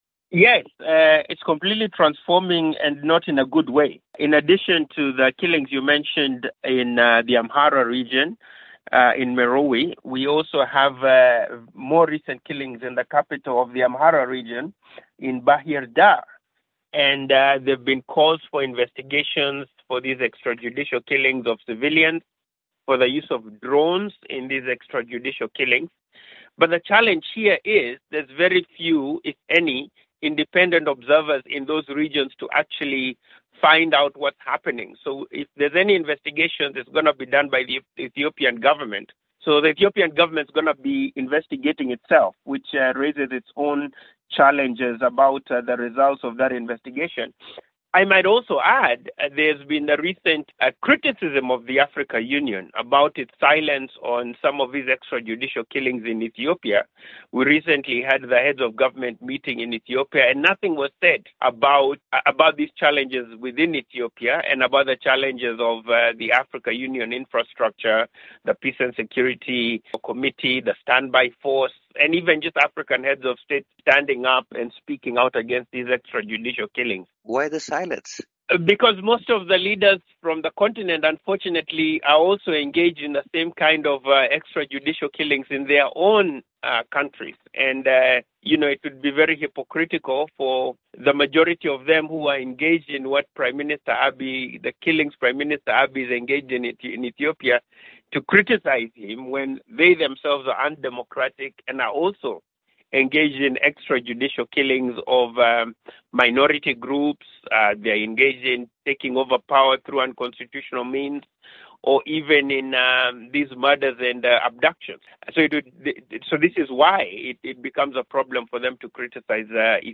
In part two of our conversation, he says there are also calls to independently investigate drone attacks and extrajudicial killings by government forces on civilians.